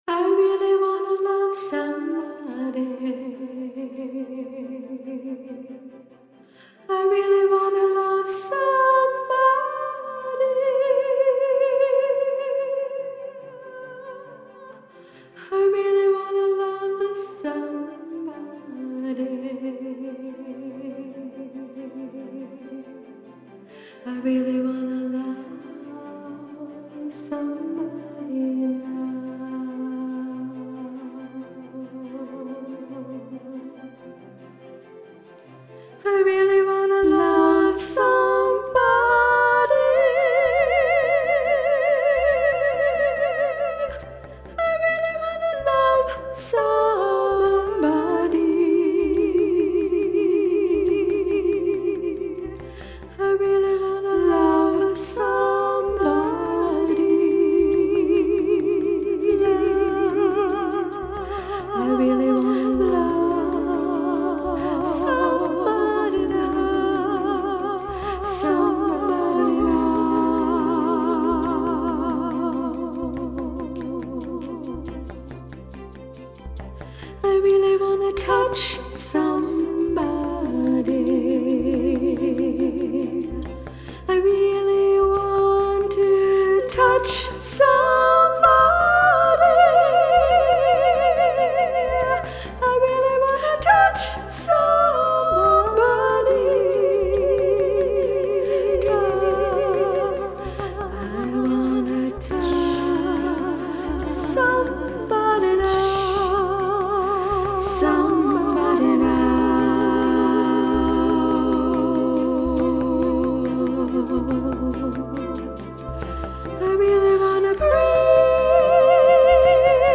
Soundscape